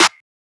Metro Snares [Supa Thick].wav